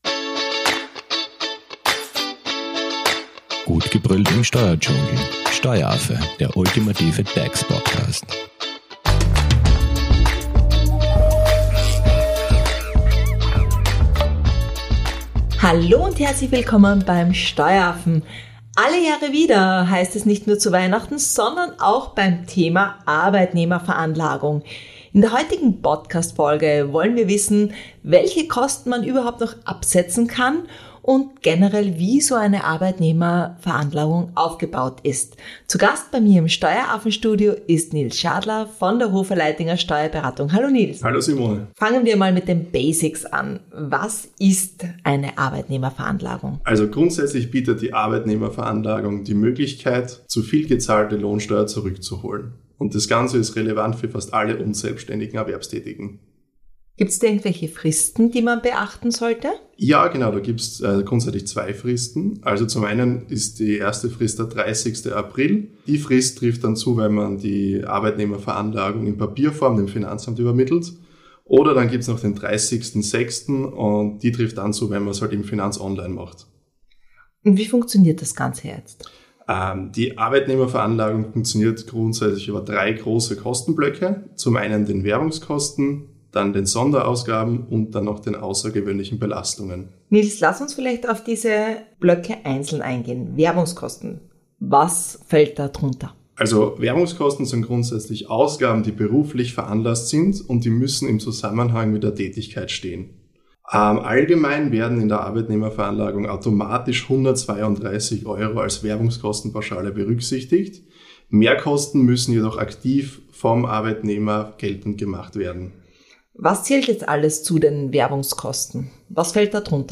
ist zu Gast im Steueraffen Studio und bringt Ordnung ins Thema Lohnsteuerausgleich.